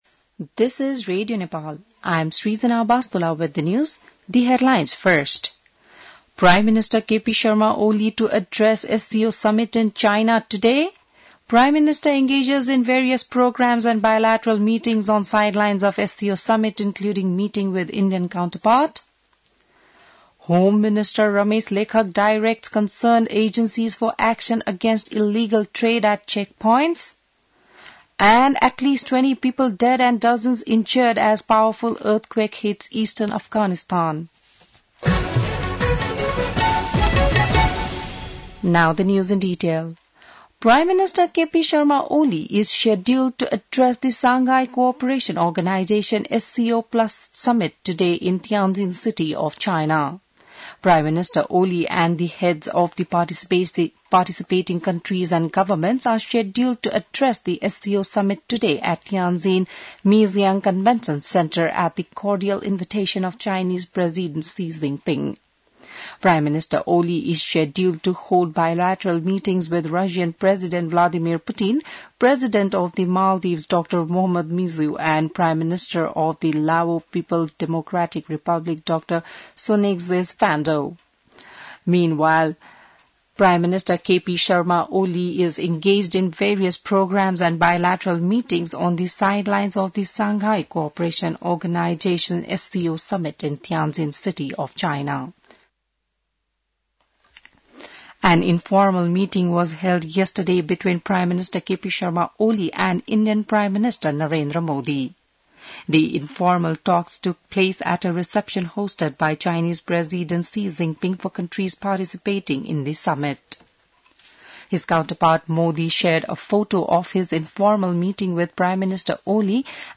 बिहान ८ बजेको अङ्ग्रेजी समाचार : १६ भदौ , २०८२